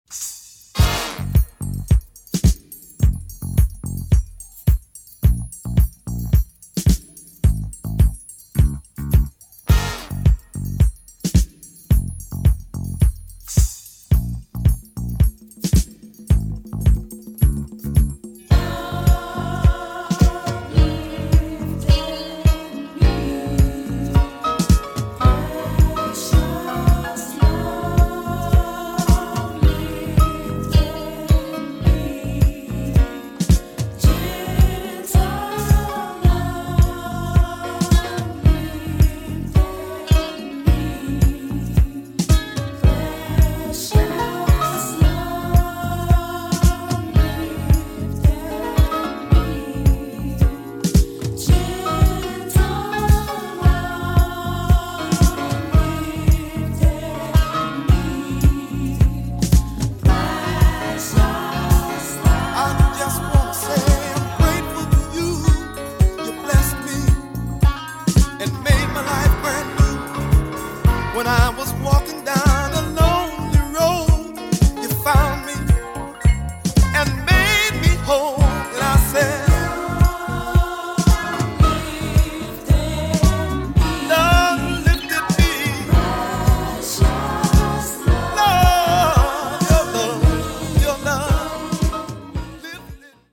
Killer electro gospel boogie funk I found in Detroit.